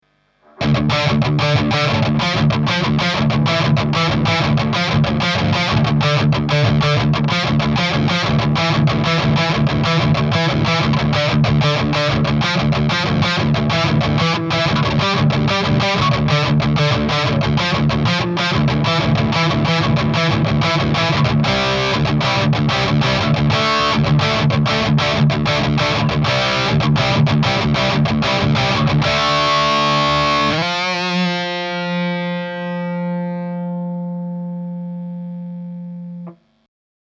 • Maxon OD-808
• IRT 60 – lead channel
• Shure SM57 on-axis and off-axis
• No filters, eq or post-processing
Dominion bridge – DropD – V30 Cap Edge 0,5 cm